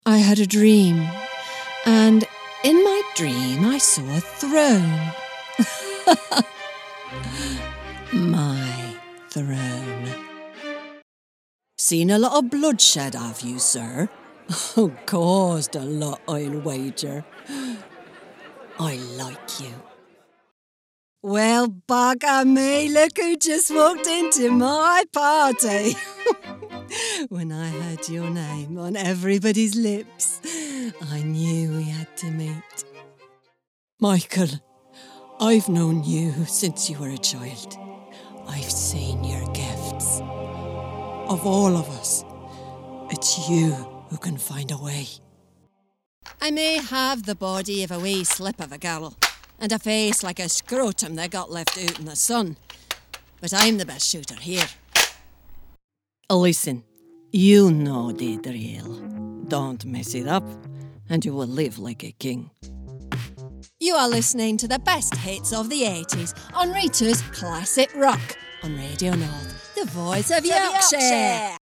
Video games - EN